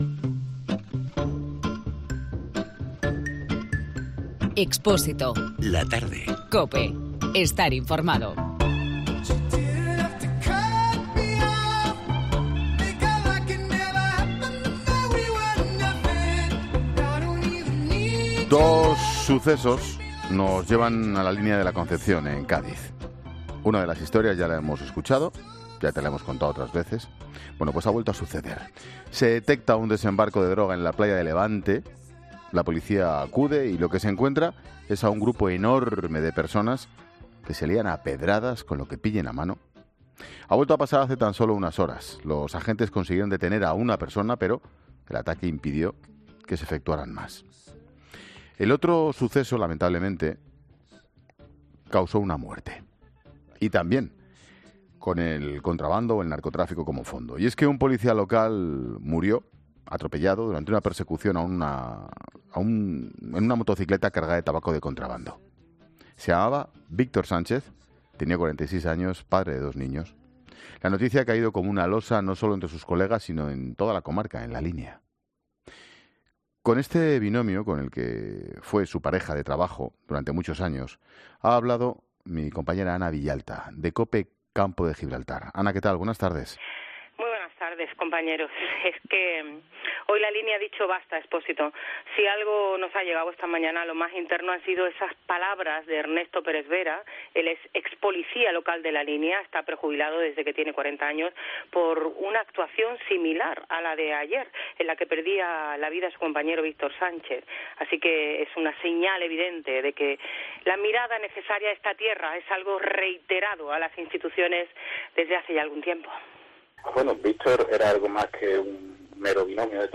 en 'La Tarde'. ESCUCHA LA ENTREVISTA COMPLETA